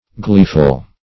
Gleeful \Glee"ful\, a.